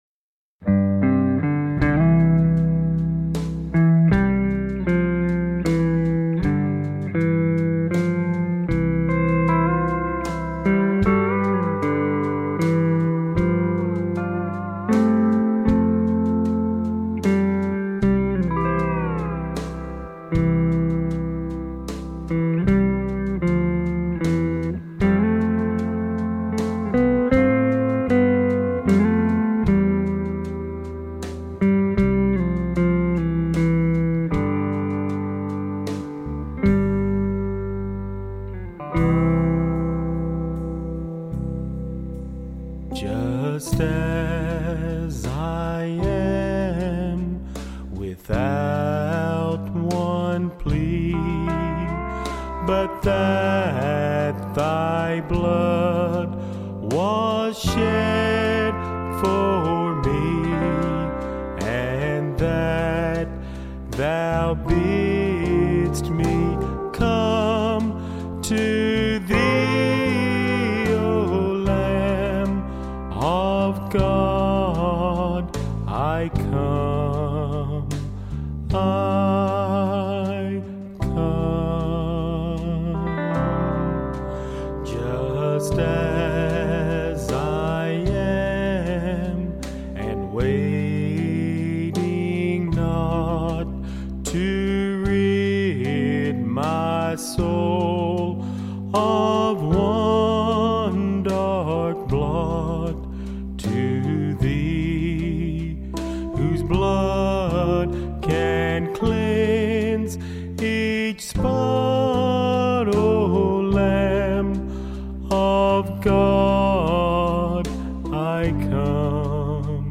Altar Call